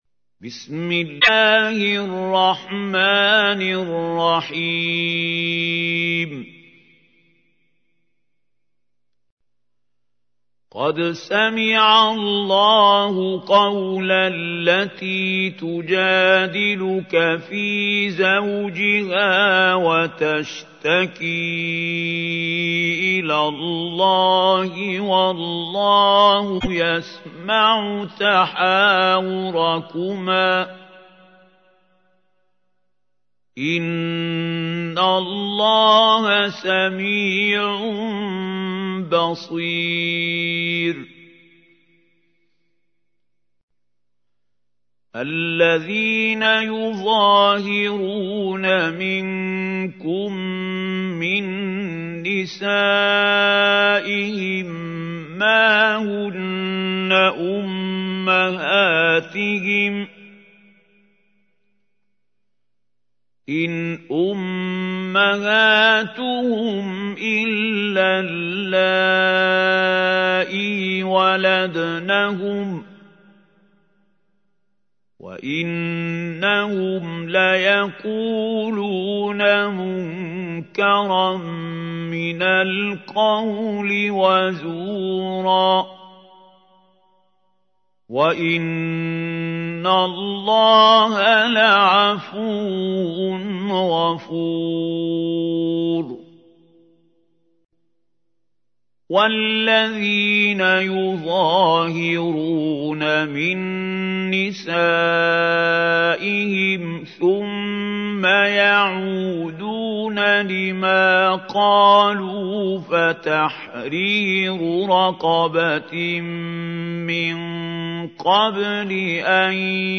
تحميل : 58. سورة المجادلة / القارئ محمود خليل الحصري / القرآن الكريم / موقع يا حسين